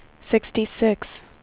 WindowsXP / enduser / speech / tts / prompts / voices / sw / pcm8k / number_86.wav
number_86.wav